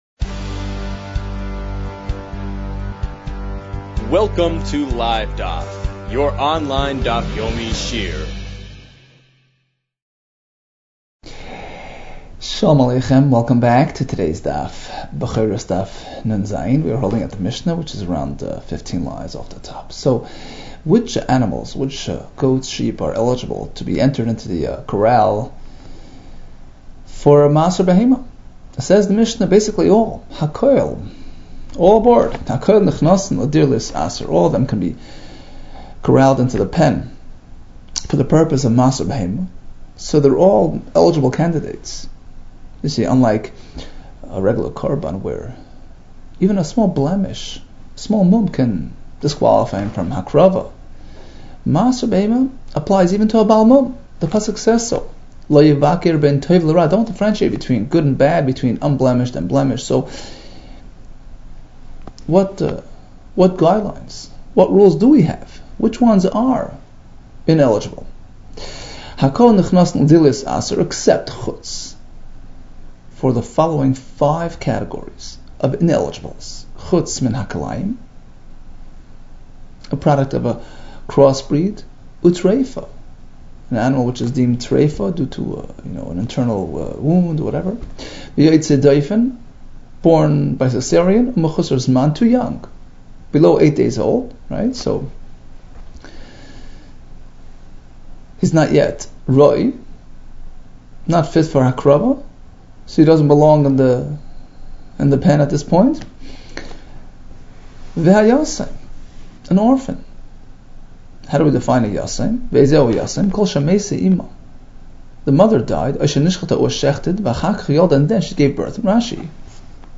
Bechoros 56 - בכורות נו | Daf Yomi Online Shiur | Livedaf